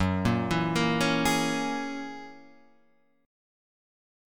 F# 9th